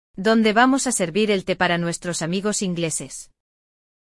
No episódio de hoje, vamos acompanhar uma conversa entre pai e filha que estão planejando receber amigos ingleses para um tradicional chá das cinco. Durante esse diálogo, exploramos vocabulário específico da cozinha, além de diferenças sutis entre palavras que podem causar confusão para falantes do português.